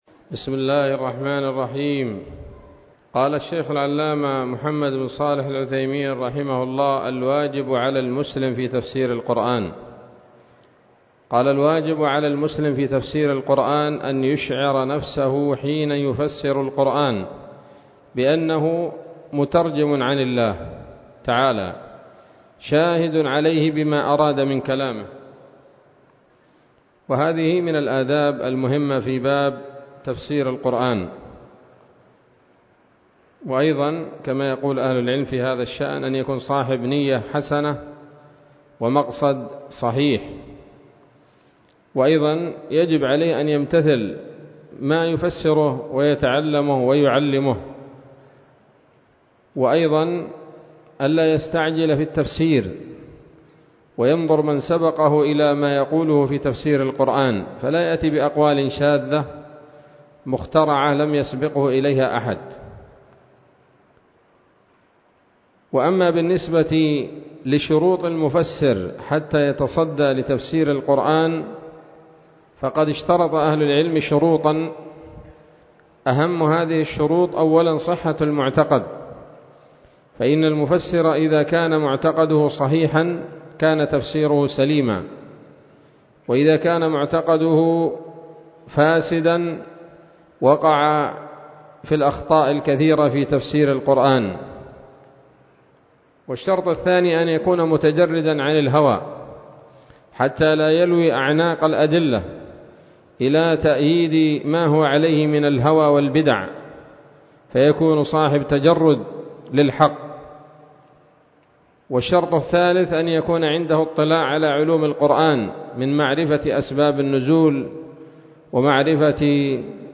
الدرس الثامن عشر من أصول في التفسير للعلامة العثيمين رحمه الله تعالى 1446 هـ